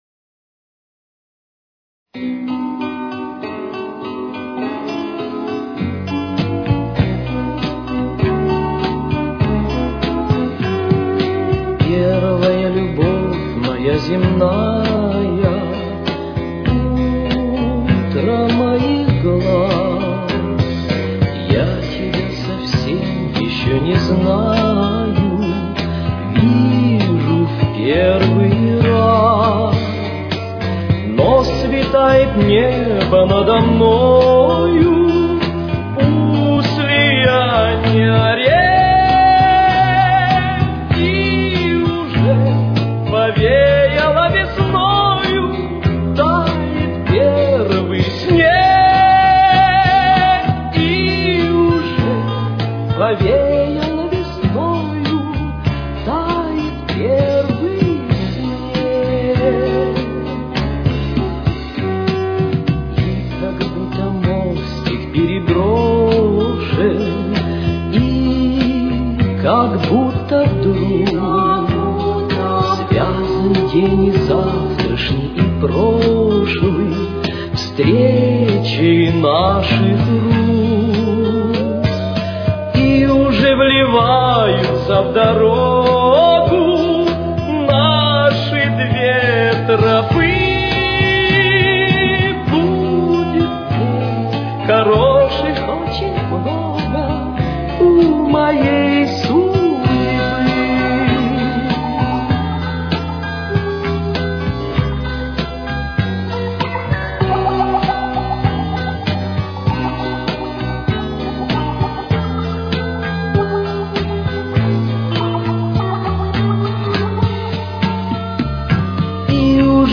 с очень низким качеством (16 – 32 кБит/с).
Тональность: Си минор. Темп: 101.